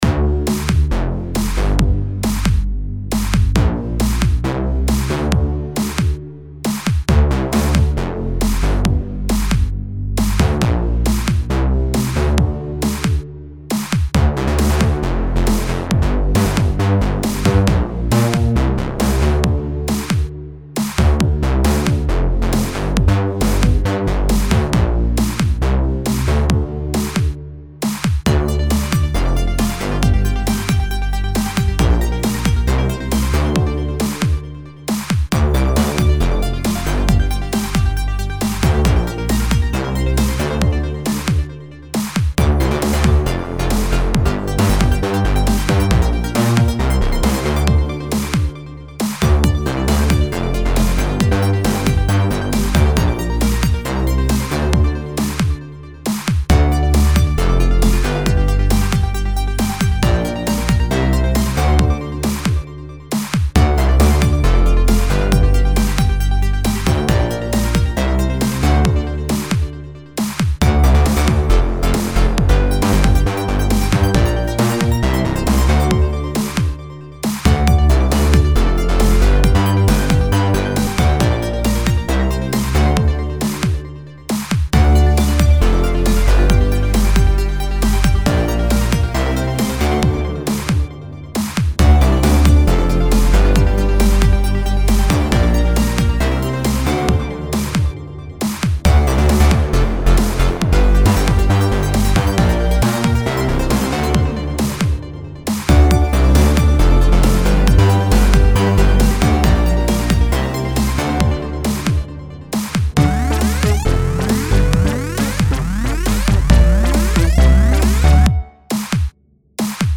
Вот, все звуки тут - пресеты с репро. Кроме ударки и пиликалки на 0:29, которая с Roland System-1 vst. Никаких эквалайзеров/компрессоров, никаких эффектов, кроме vss3 рума на паре звуков.
Там где эффекты слышны - значит так в пресете на синте было. На мастере тоже ничего нет.